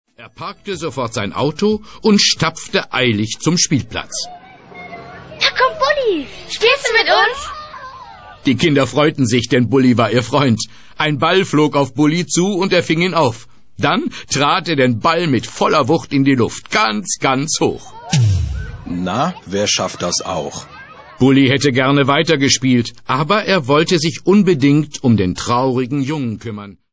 Das Hörbuch "Schutzbär Bulli", CD zum Buch